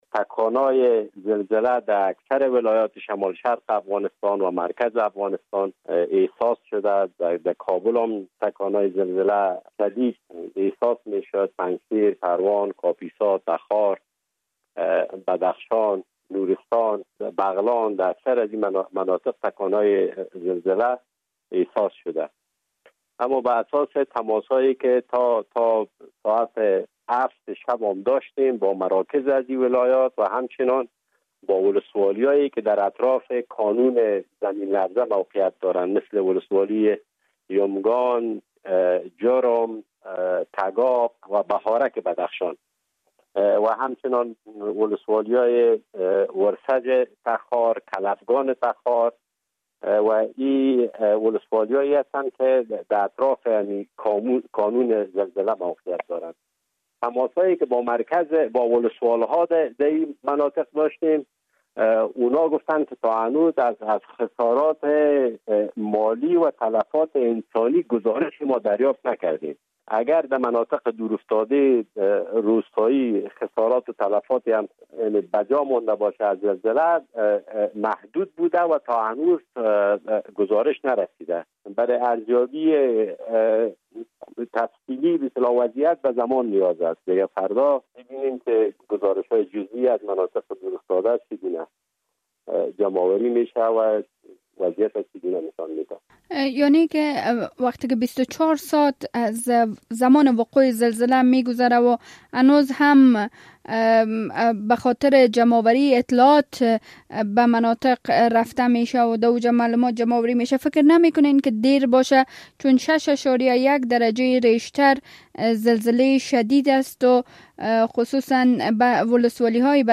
مصاحبه - صدا
مصاحبه رادیو آزادی با نجیب آقا فهیم وزیر دولت در امور رسیدگی به حوادث طبیعی افغانستان